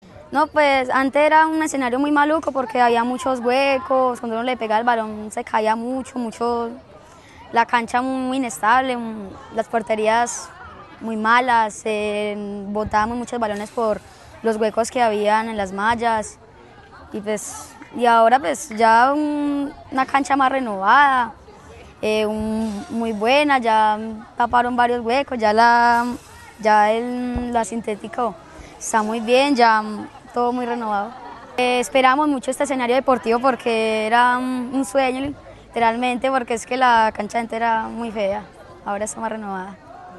deportista local.